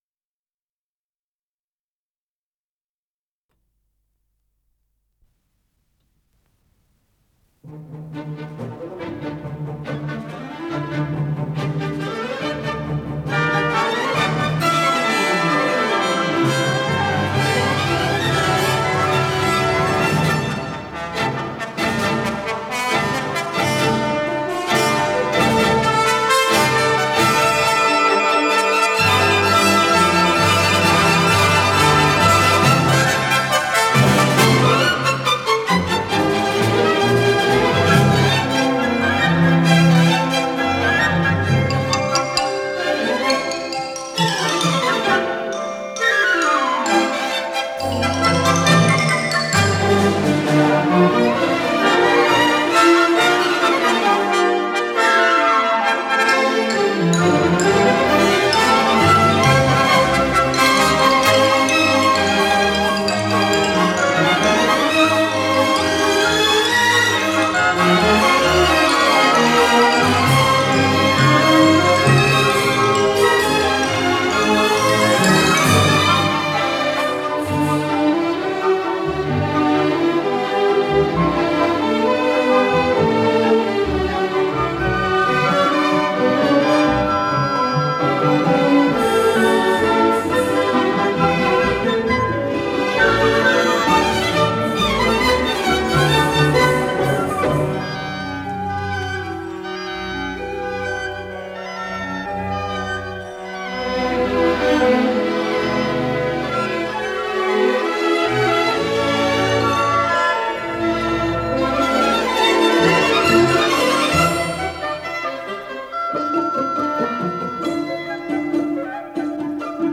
ПодзаголовокДо мажор
Скорость ленты38 см/с
ВариантДубль моно